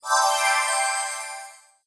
fishing_catcg_eff.wav